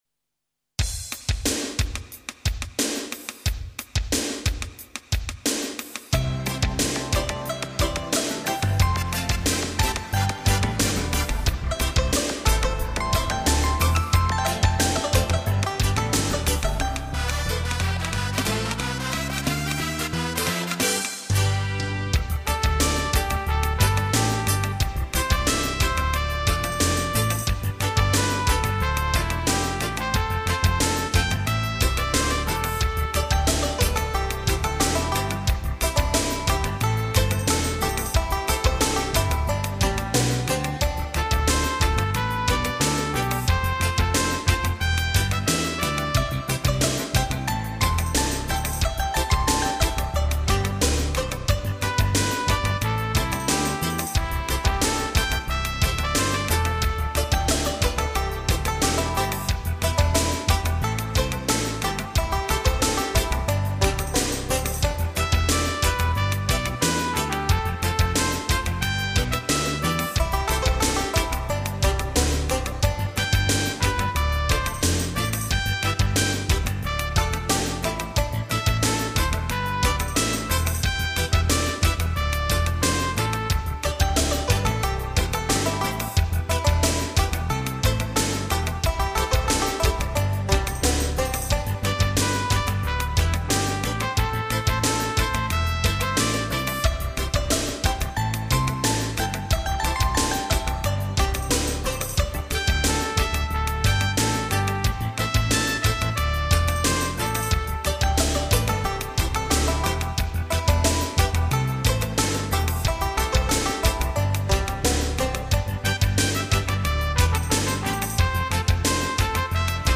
评剧 中四